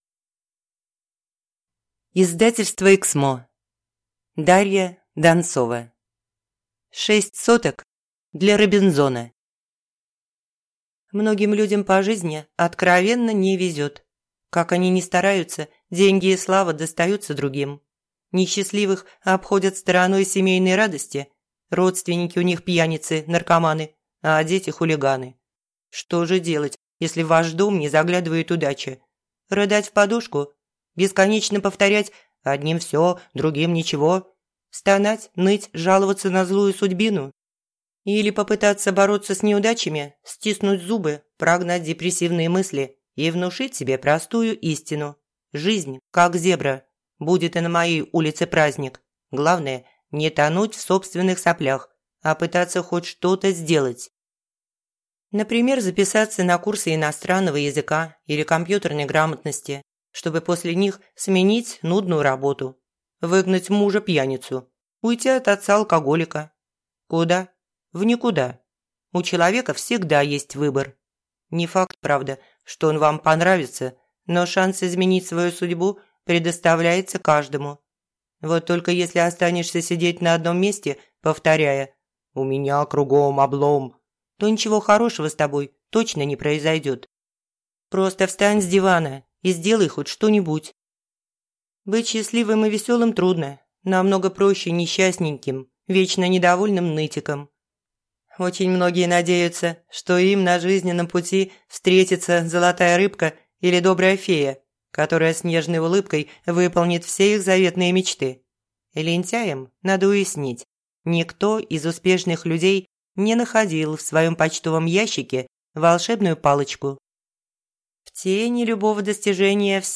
Аудиокнига Шесть соток для Робинзона - купить, скачать и слушать онлайн | КнигоПоиск